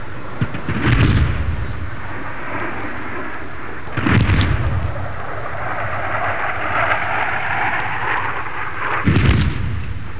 دانلود آهنگ طیاره 40 از افکت صوتی حمل و نقل
جلوه های صوتی
دانلود صدای طیاره 40 از ساعد نیوز با لینک مستقیم و کیفیت بالا